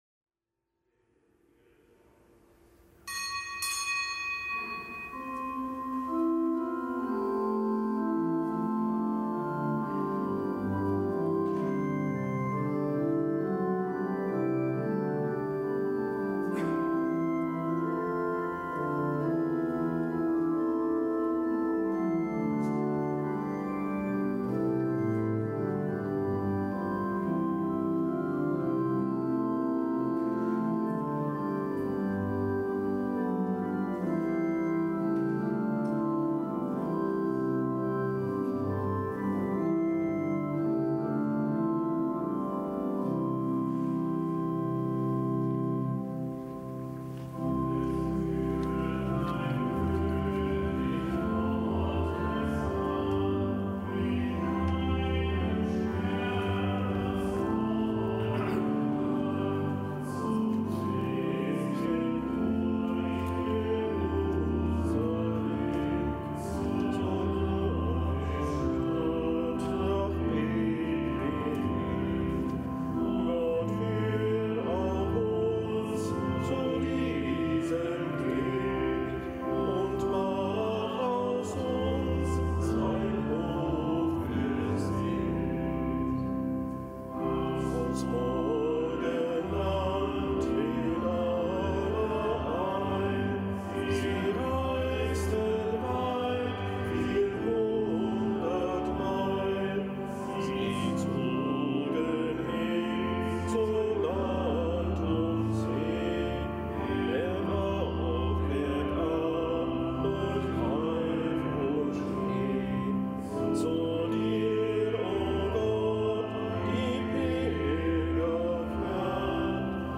Kapitelsmesse aus dem Kölner Dom am Donnerstag der Weihnachtszeit. Nichtgebotener Gedenktag des Hl. Severin, Mönch in Norikum (RK). Zelebrant: Weihbischof Rolf Steinhäuser.